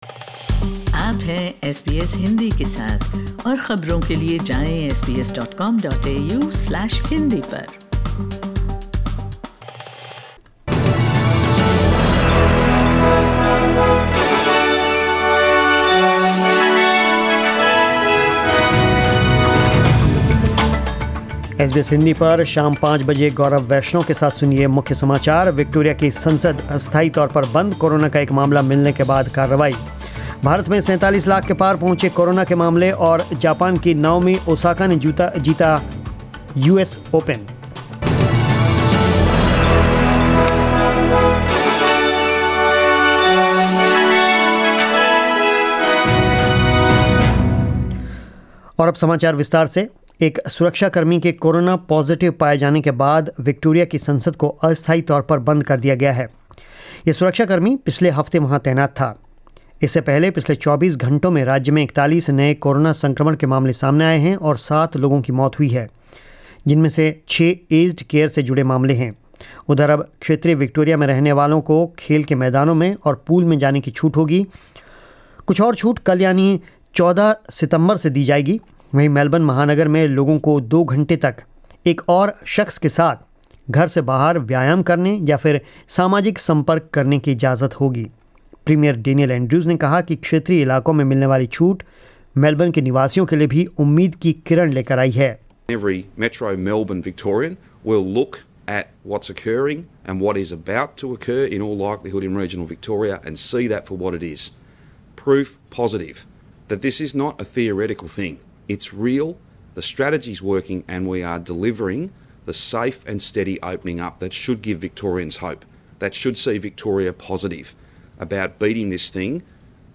News in Hindi 13 September 2020